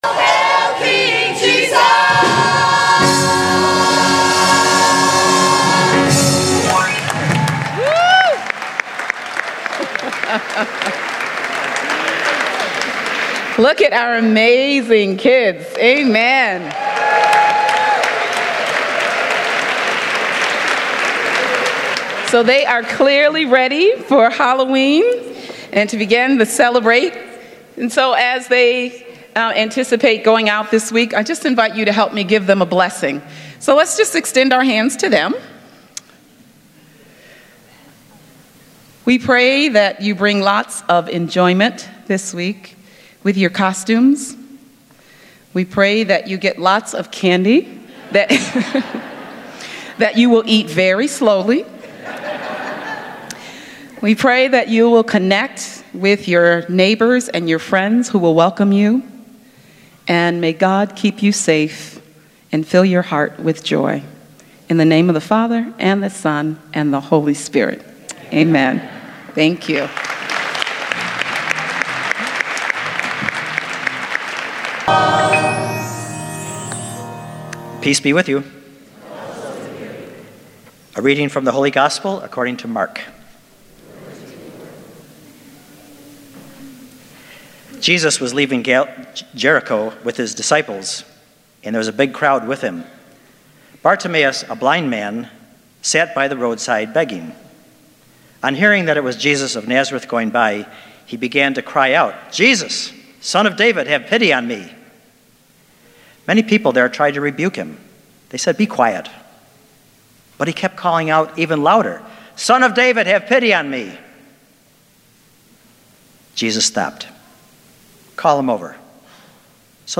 Spiritus Christi Mass October 28th, 2018